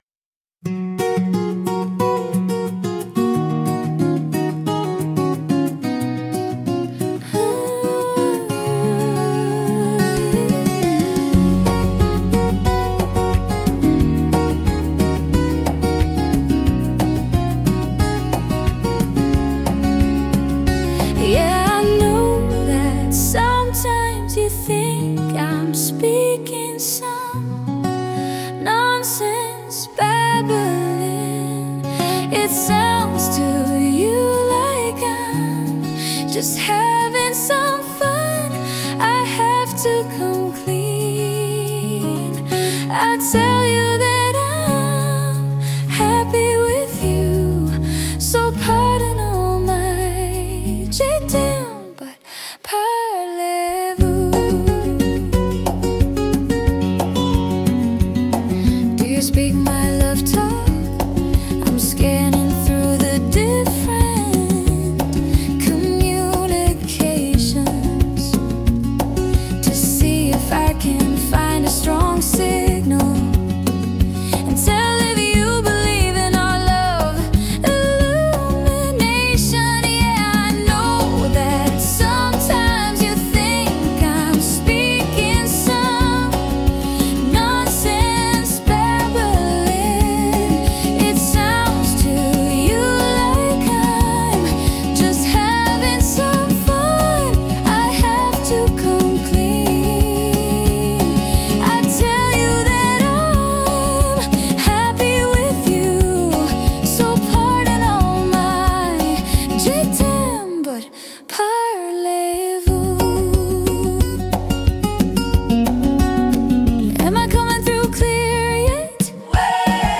studio version
one of the softer songs